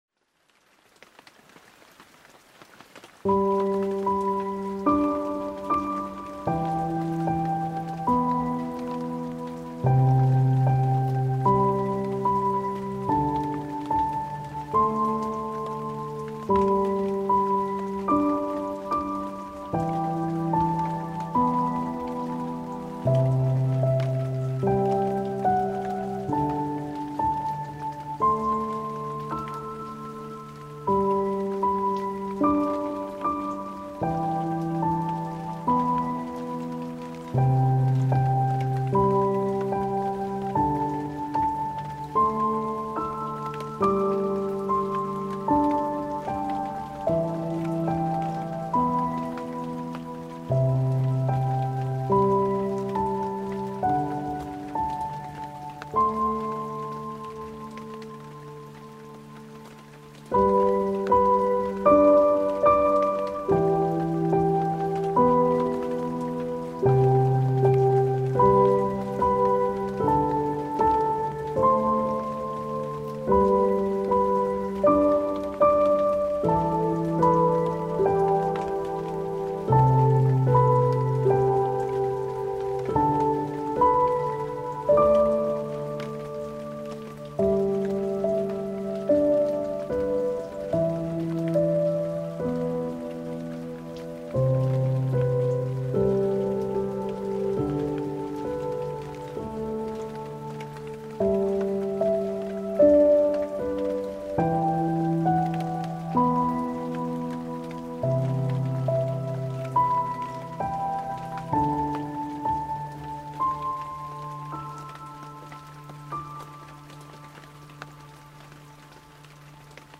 12-Peaceful-Piano-With-Rain-Sounds.mp3